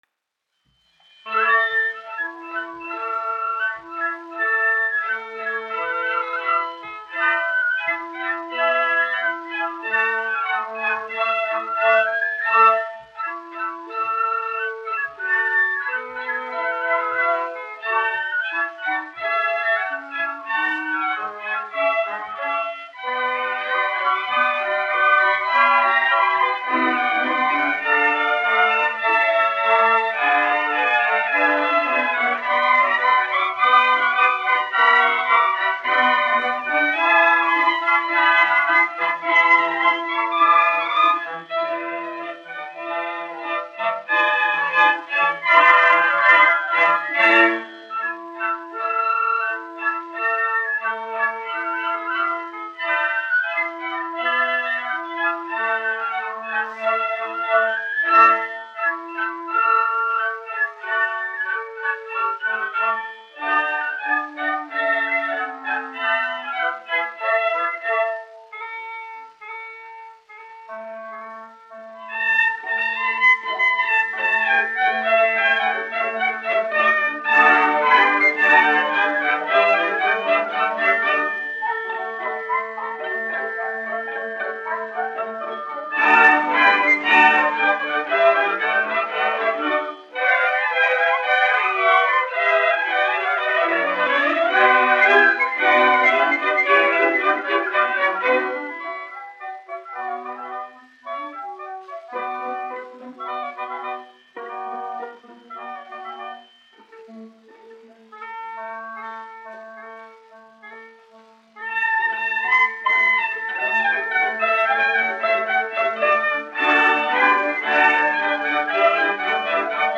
1 skpl. : analogs, 78 apgr/min, mono ; 25 cm
Orķestra mūzika
Svītas (orķestris)--Fragmenti
Skaņuplate